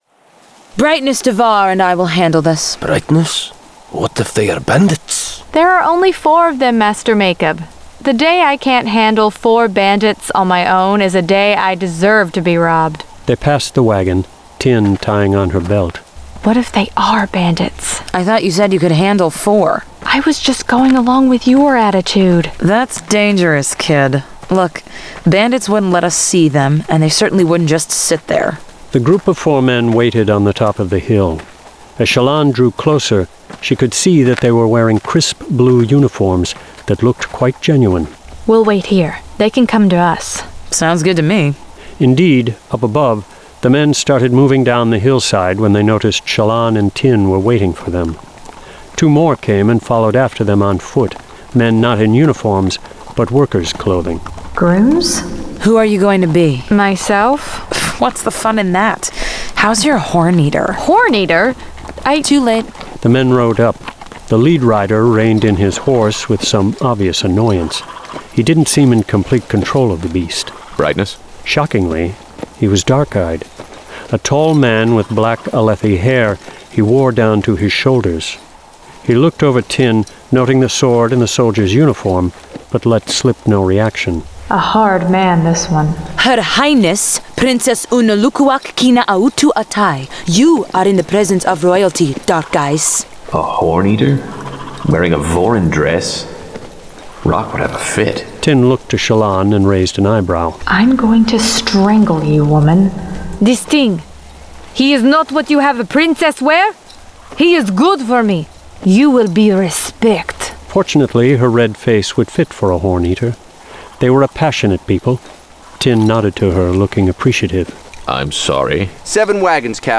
Full Cast. Cinematic Music. Sound Effects.
[Dramatized Adaptation]
Genre: Fantasy